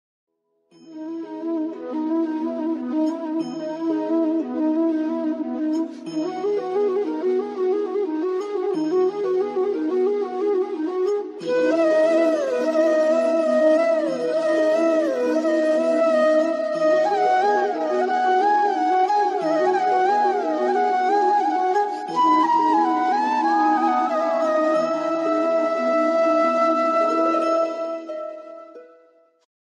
ArtistTraditional Flute Instrumental